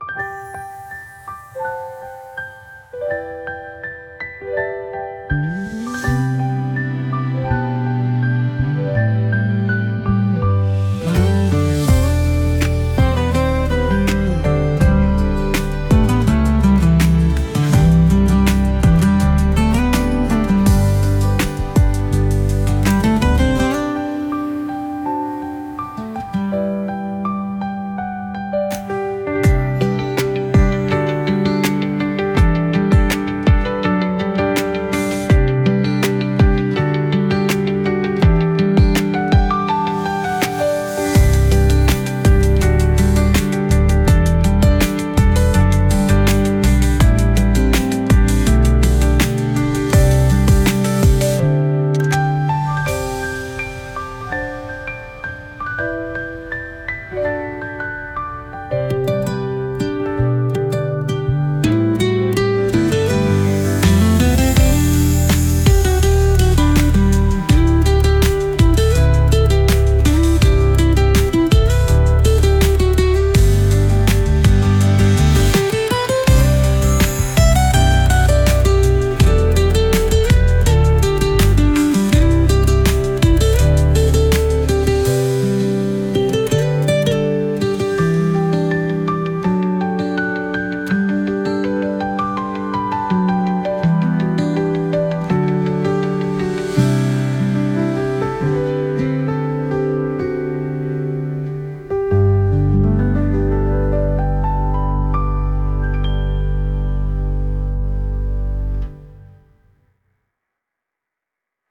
ゲーム音楽に王宮っぽいのを作りたかったのですが私の実力では無理でした…